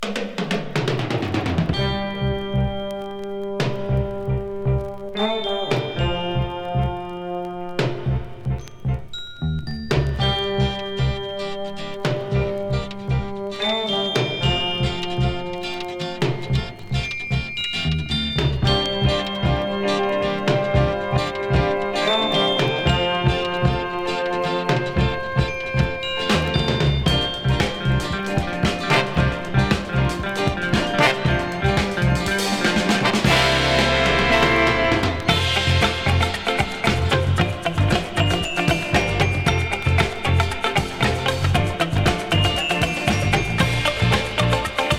音の豪勢さと大人の遊びみたいな印象すらあるエンタメ感満載なレコード。
Rock, Stage & Screen　USA　12inchレコード　33rpm　Mono